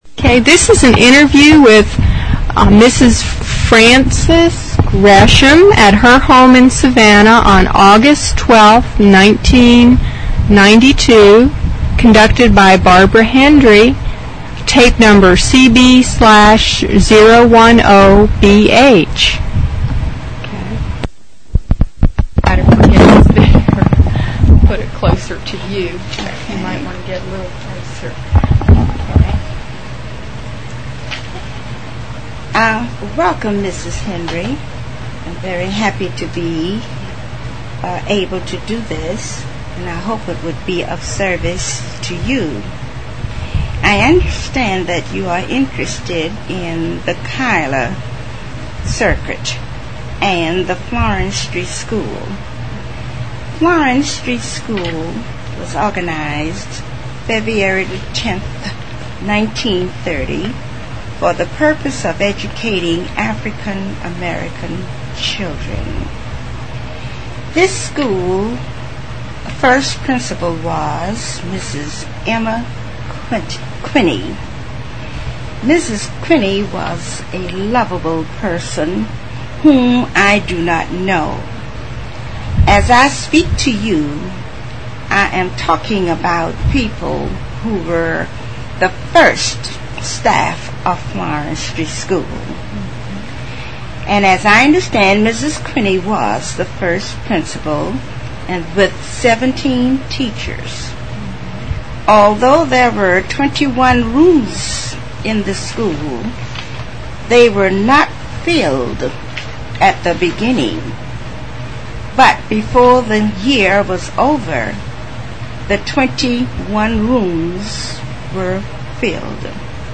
Interview
Object Name Tape, Magentic Credit line Courtesy of City of Savannah Municipal Archives Copyright Requests to publish must be submitted in writing to Municipal Archives.